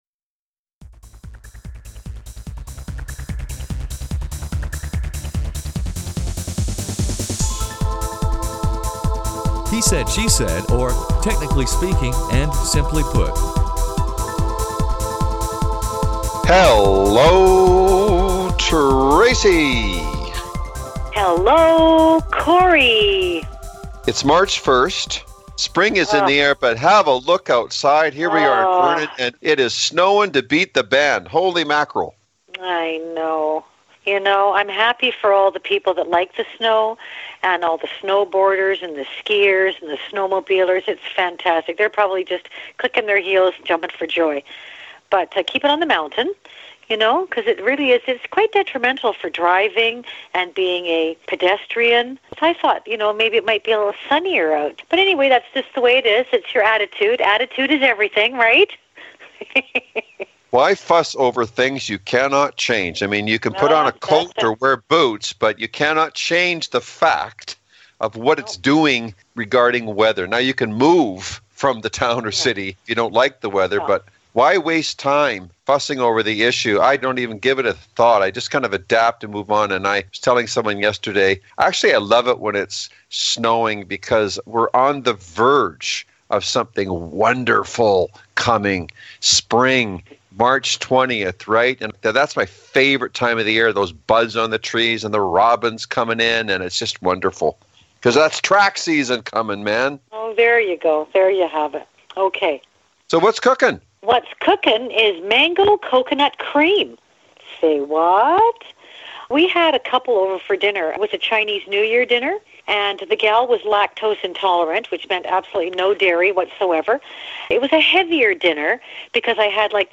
spontaneous and humorous dialog